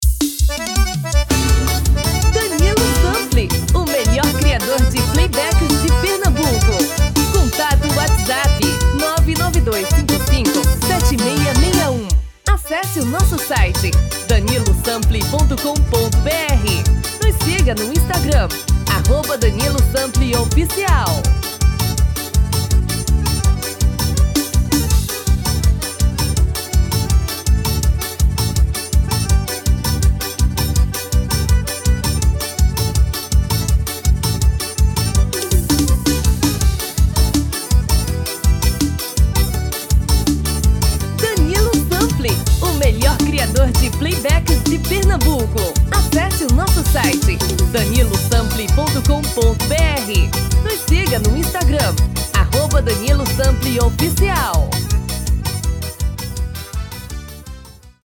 DEMO 1: tom original / DEMO 2: tom feminino